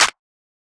TS Clap_9.wav